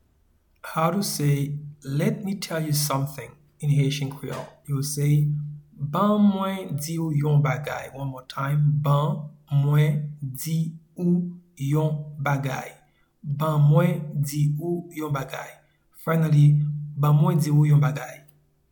Pronunciation and Transcript:
Let-me-tell-you-something-Ban-mwen-di-ou-yon-bagay.mp3